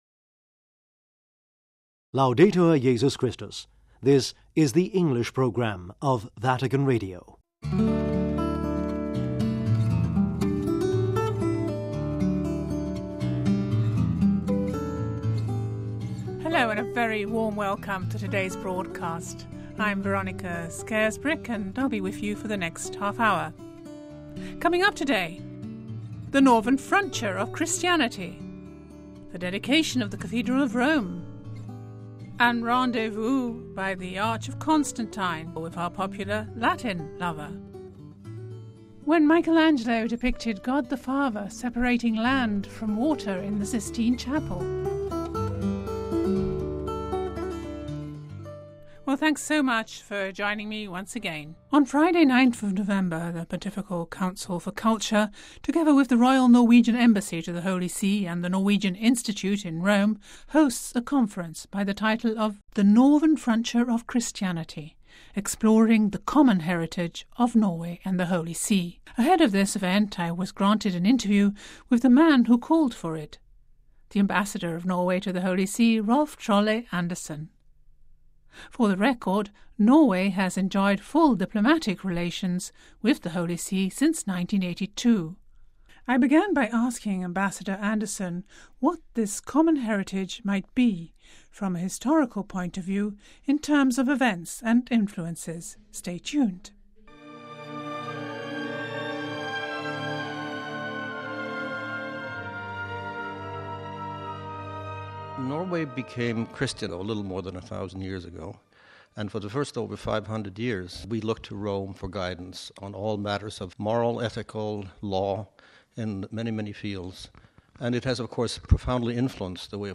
The Northern frontier of Christianity - Exploring the common heritage of Norway and the Holy See in an interview with the Norwegian Ambassador to the Holy See